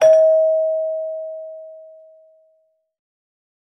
Звуки полиграфа
Звук правильного ответа на полиграфе (без лжи)